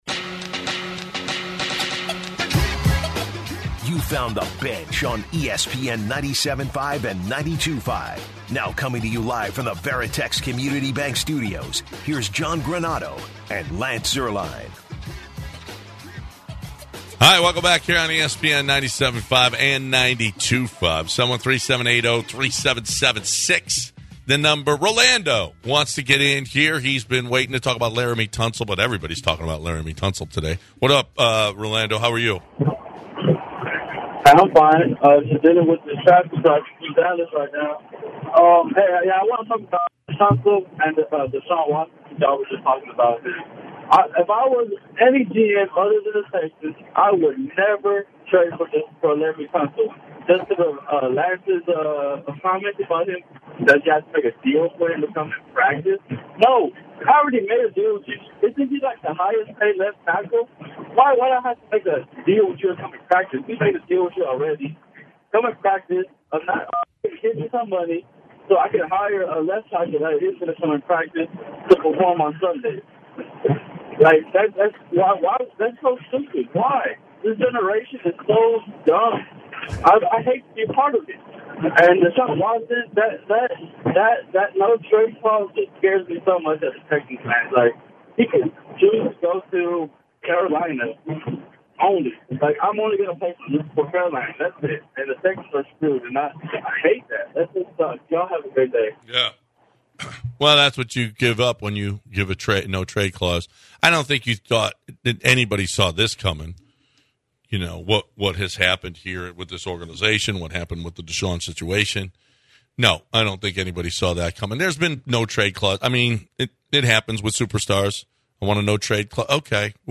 They take some calls on trades the Houston Texans could make for Deshaun Watson.